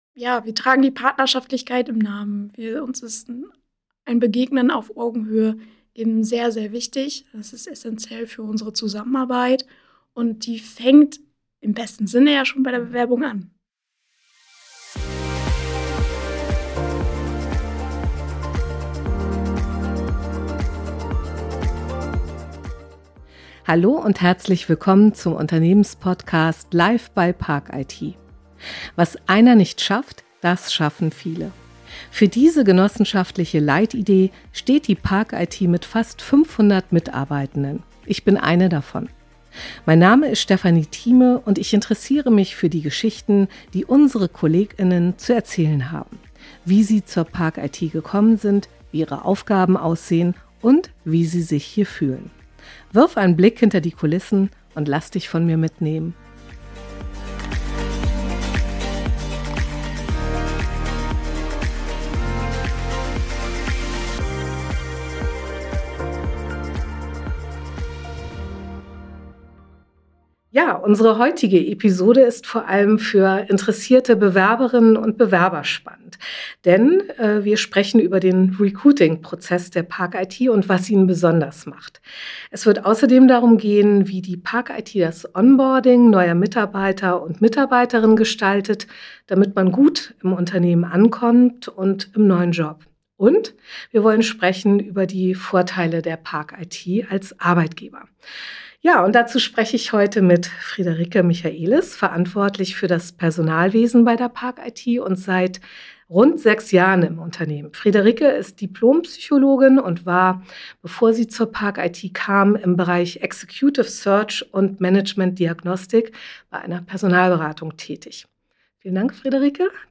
Im Gespräch beschreibt sie, wie neue Kolleg*innen durch ein strukturiertes Onboarding von Anfang an optimal ins Team eingebunden werden.